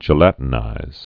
(jə-lătn-īz, jĕlə-tn-īz)